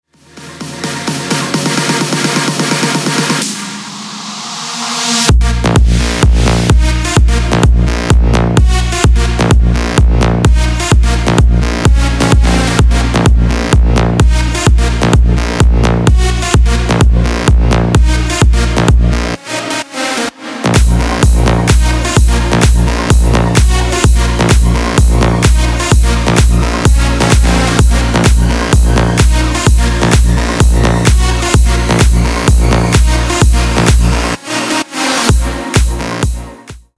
• Качество: 320, Stereo
electro house